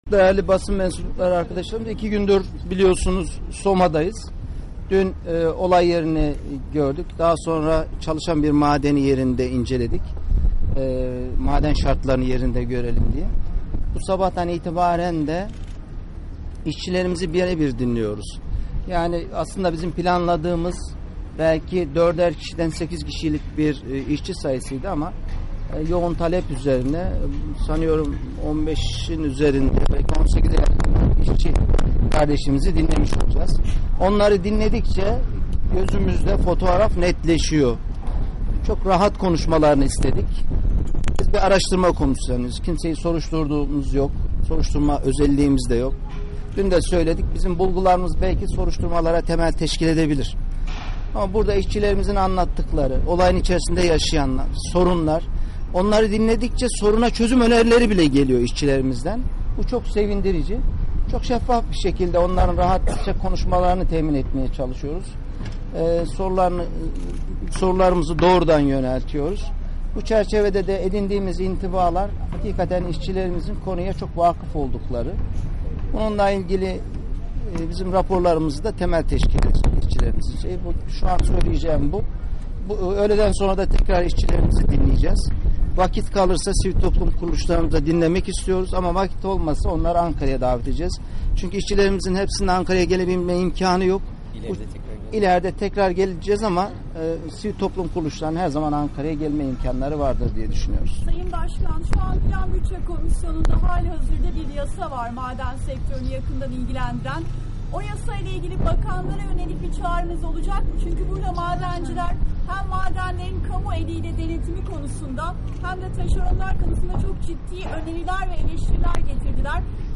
Ali Rıza Alaboyun Basın Açıklaması